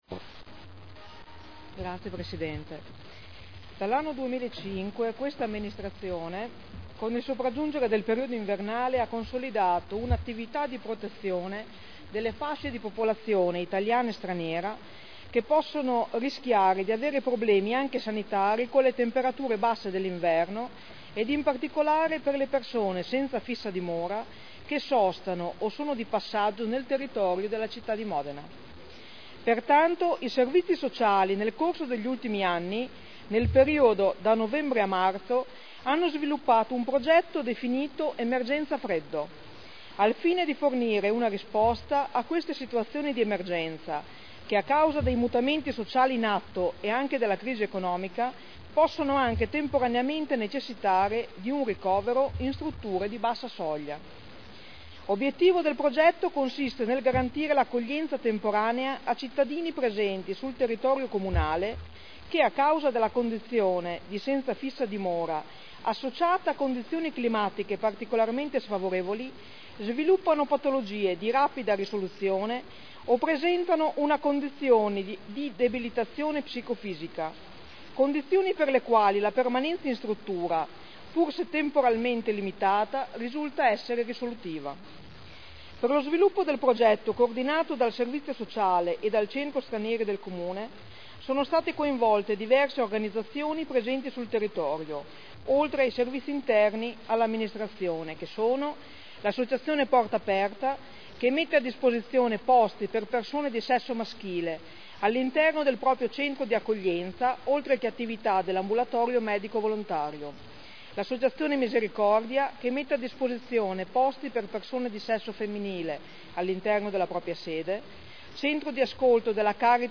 Seduta del 18/01/2010.